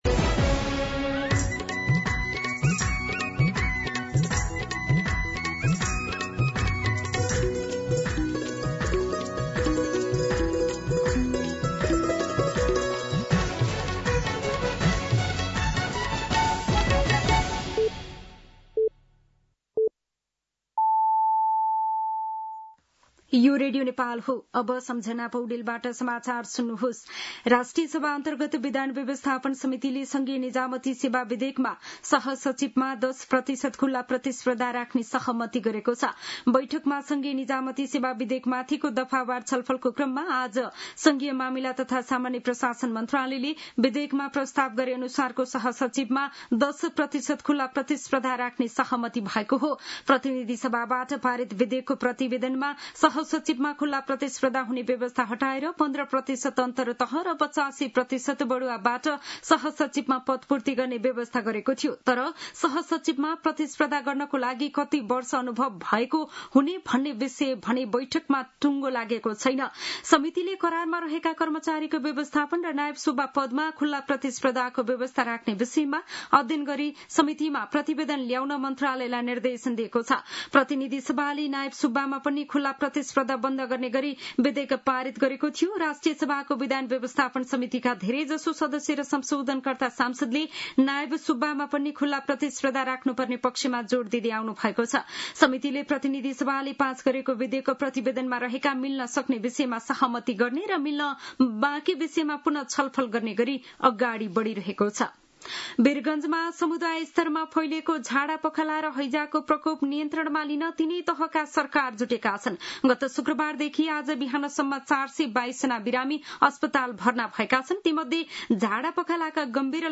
दिउँसो ४ बजेको नेपाली समाचार : ११ भदौ , २०८२
4pm-News-05-11.mp3